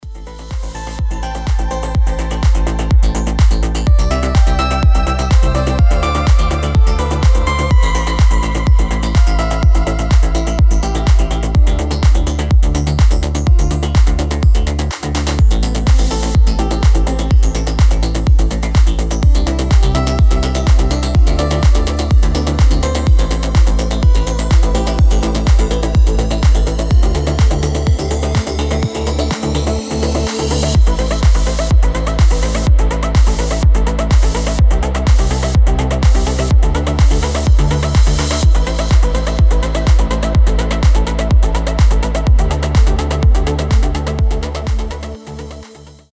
• Качество: 320, Stereo
Electronic
без слов
melodic trance